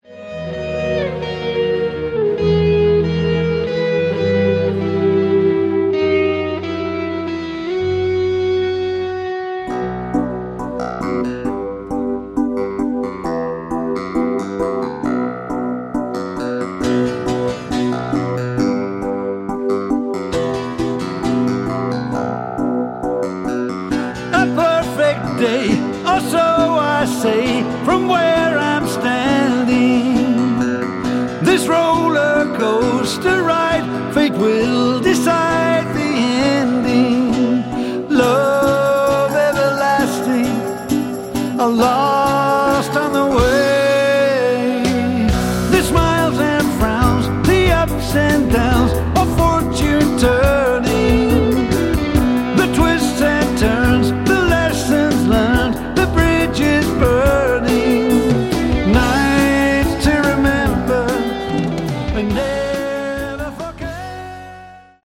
Category: AOR
bass and lead vocals
guitars
keyboards
drums
Recorded May 4, 2010 in Basel, Switzerland.
live